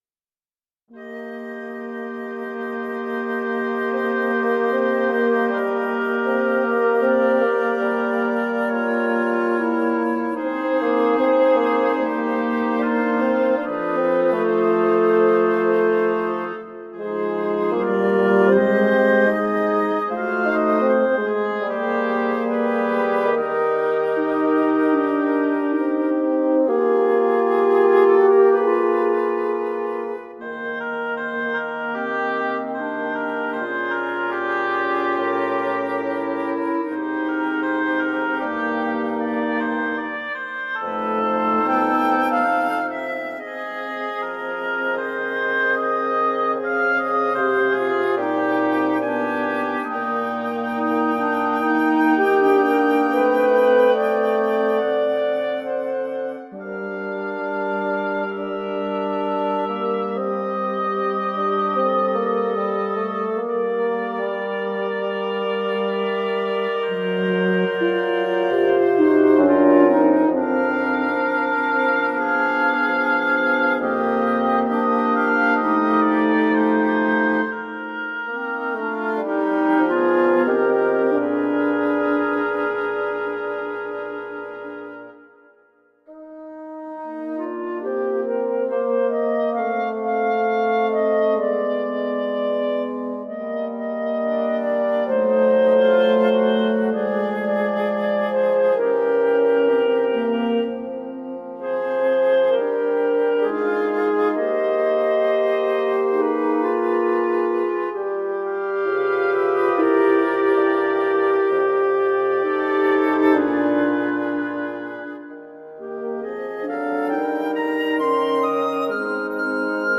【編成】木管五重奏（Flute, Oboe, Clarinet, Horn, Fagotto/Bassoon)
ヴァース（冒頭〜Cセクション）とコーラス（Dセクション以降）で構成されています。
木管アンサンブル　ジャズ　ジャズバラード